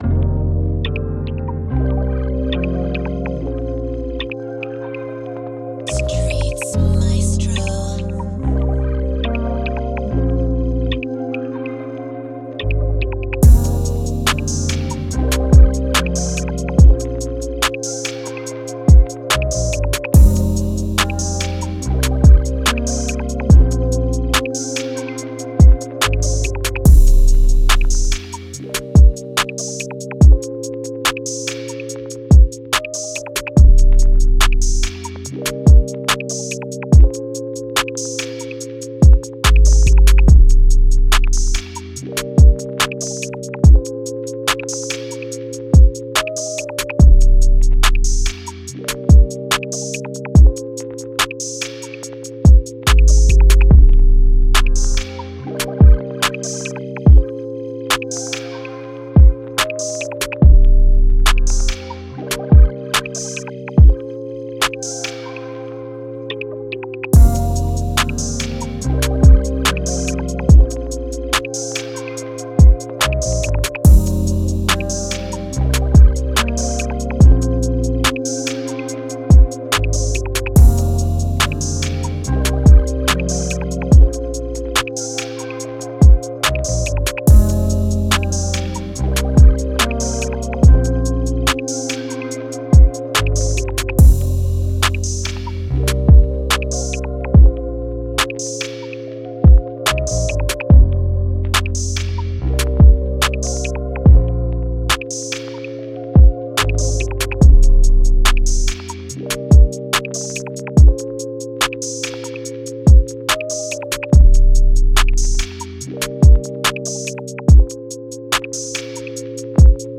Moods: laid back, smooth, emotional
Genre: R&B
Tempo: 143